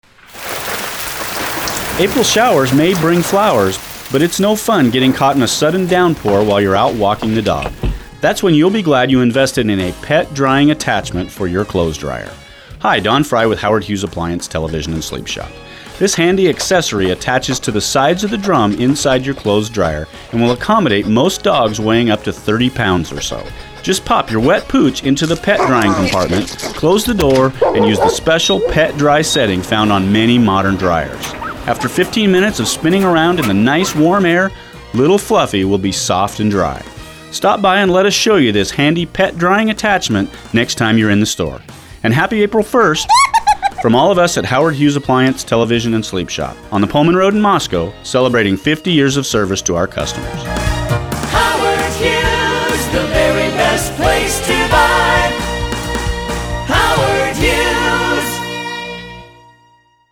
This is one of eight different commercials running heavily all day April 1st on all six commercial radio stations in the market.
Though the advertiser’s delivery and jingle sound the same as always, the copy is…a little different.